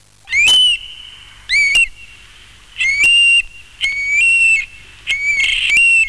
Quando passa in volo sopra a casa emette un fischio forte e sonoro.
Fischio 127KB )
occhione[1].wav